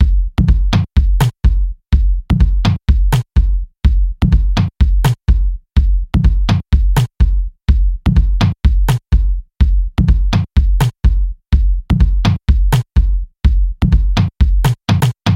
金属凸起部分
描述：典型的金属鼓是由汤姆鼓、小鼓和大鼓组成的。
Tag: 150 bpm Heavy Metal Loops Drum Loops 1.08 MB wav Key : Unknown